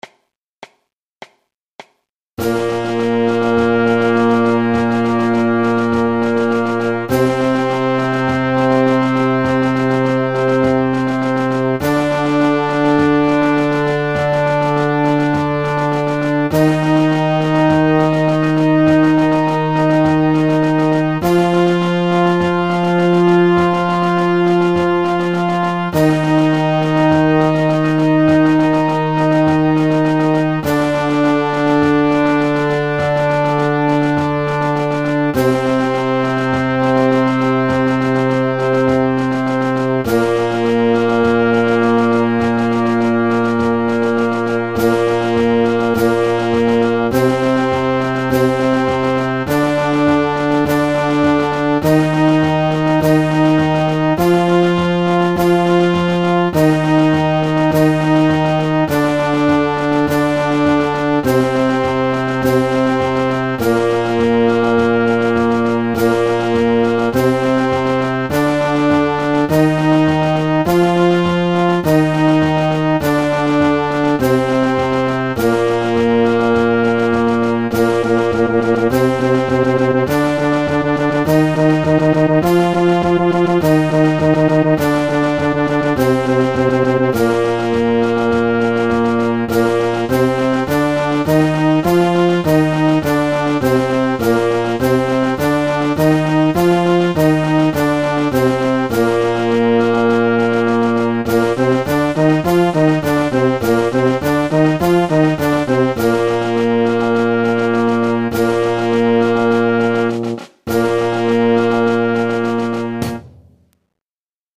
Warm-up 3 (medium).mp3